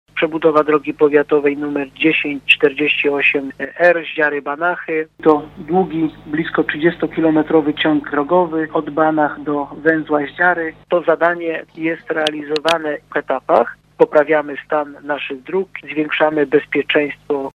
Starosta niżański poinformował, że był to kolejny etap przebudowy tej blisko 30 kilometrowej drogi: